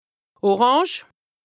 2APRESTA_OLCA_LEXIQUE_INDISPENSABLE_HAUT_RHIN_127_0.mp3